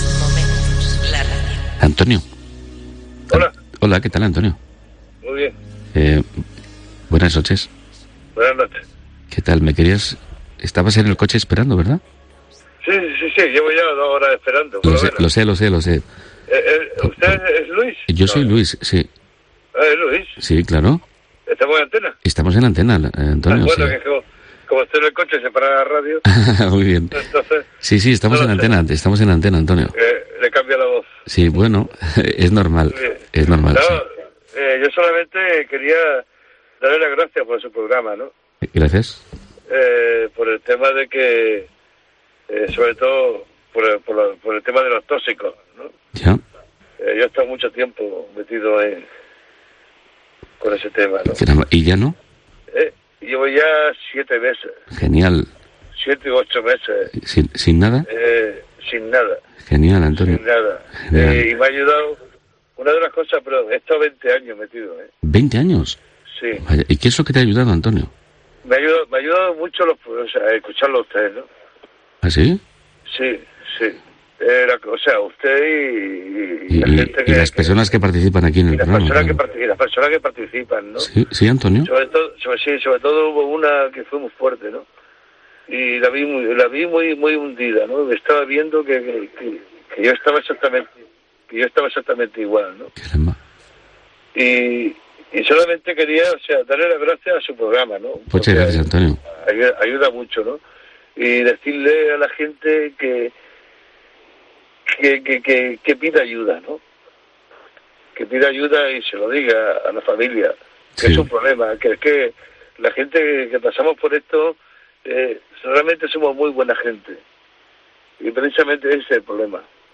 La radio a oscuras...
La madrugada, en cambio, es el tiempo de la calma, de la reflexión, el insomnio, la soledad, el darle vueltas a los problemas...